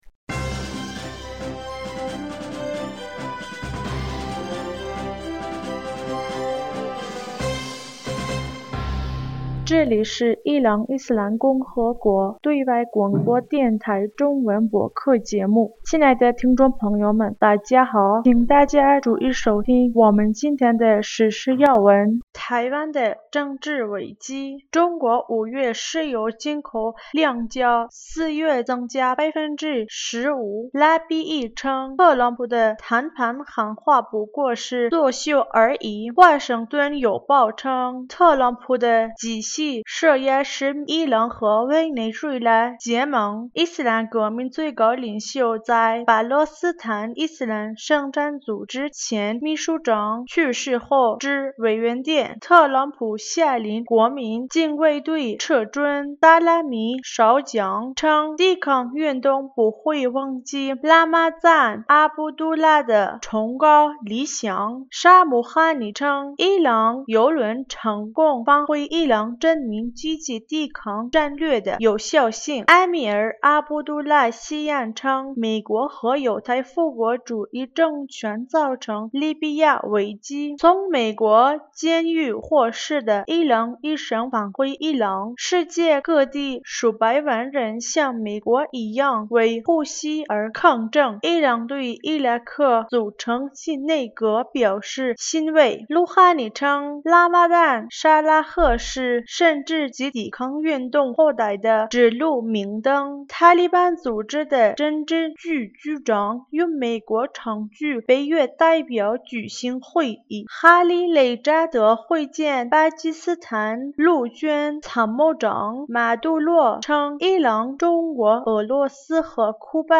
2020年6月8日 新闻